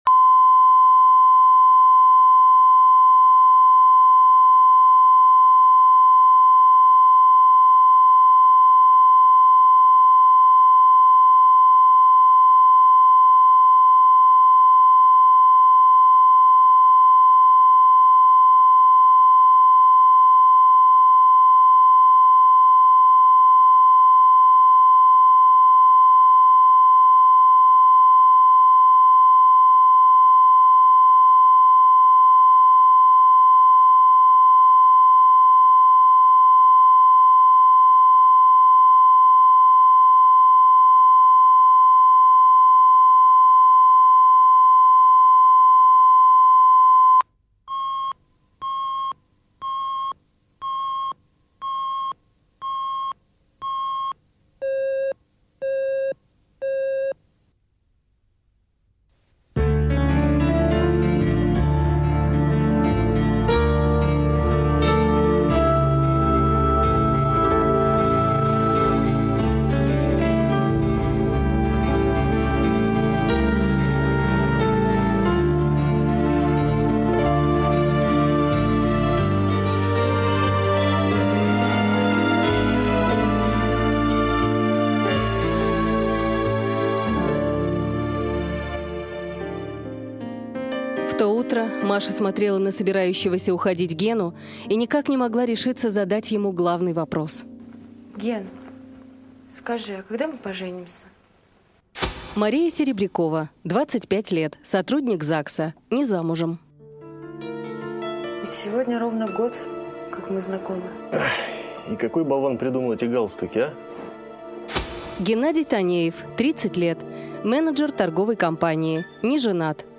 Аудиокнига Привычка выходить замуж | Библиотека аудиокниг